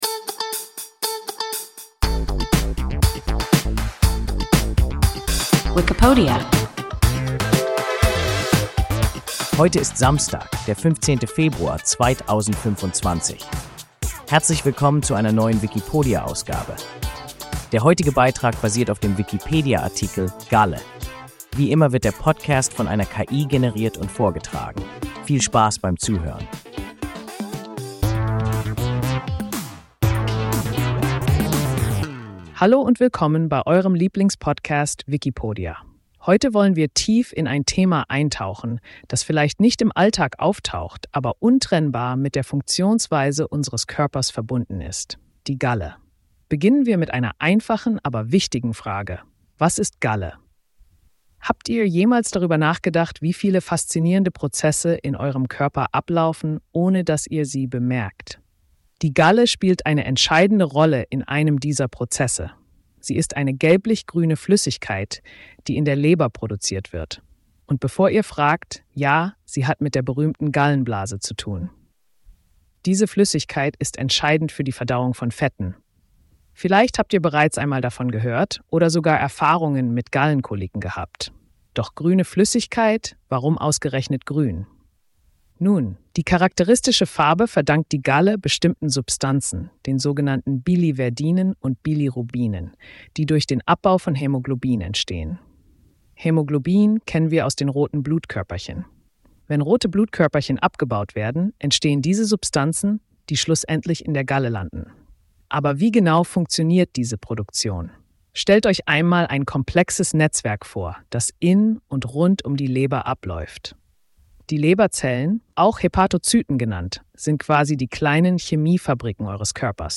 Galle – WIKIPODIA – ein KI Podcast